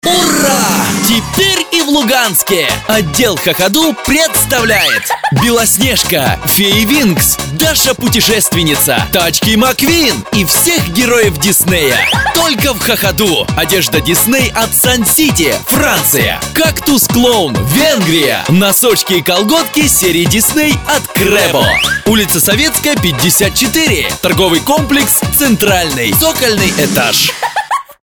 Аудио ролик для магазина детской одежды Хохаду